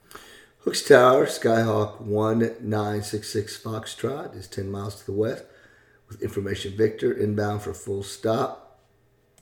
Aviation Radio Calls
13_PilotTower10MilesWest.mp3